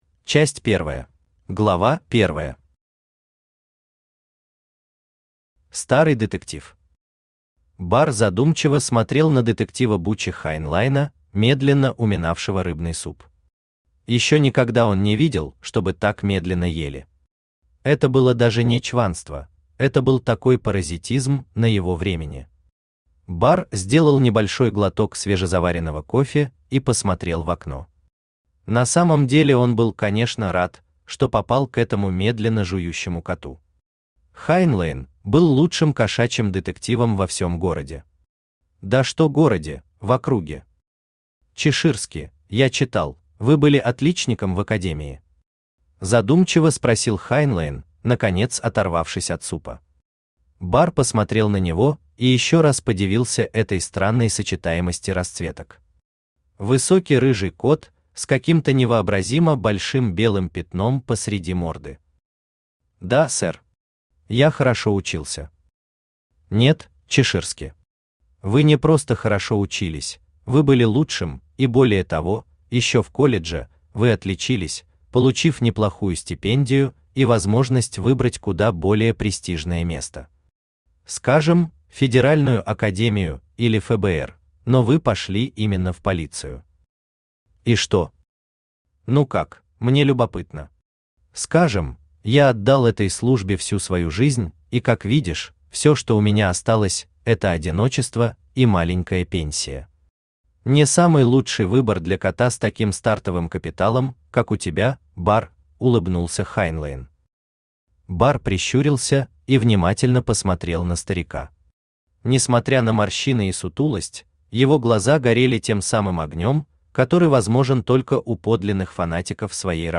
Аудиокнига Бар Чеширски. Первое дело | Библиотека аудиокниг
Первое дело Автор Даниил Заврин Читает аудиокнигу Авточтец ЛитРес.